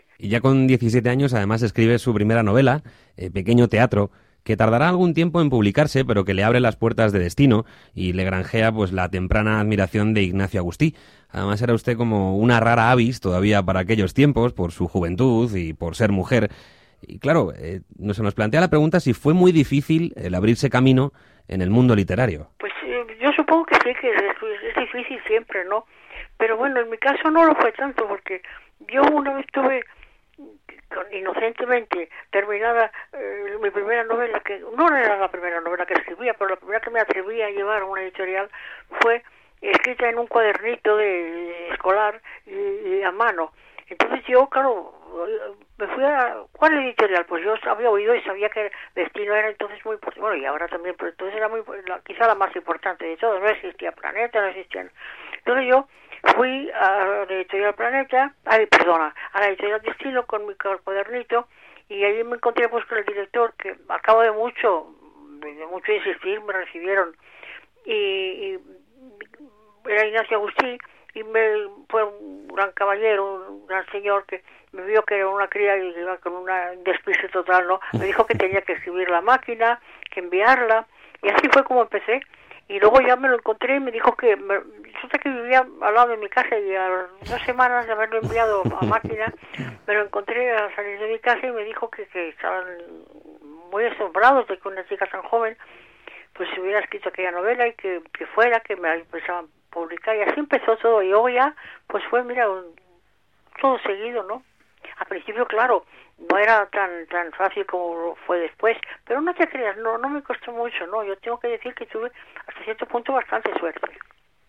Entrevista a Ana María Matute: un regalo para el día del libro